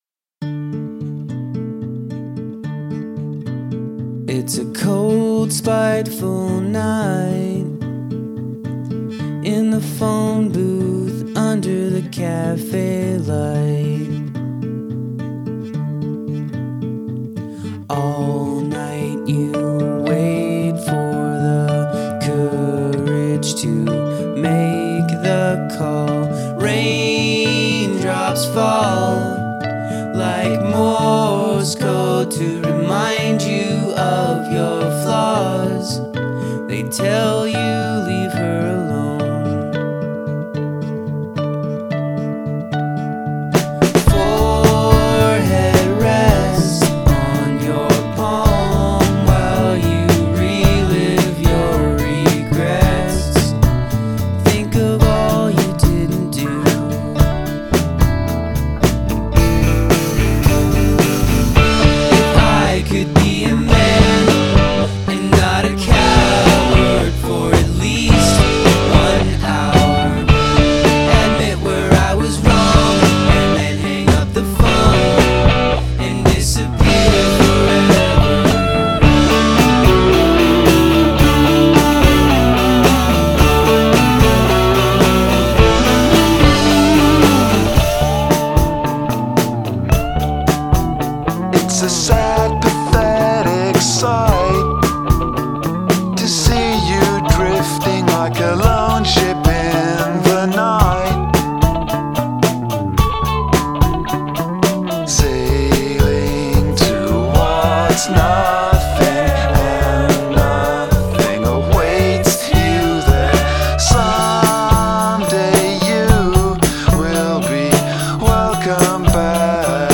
jangly indie pop
indie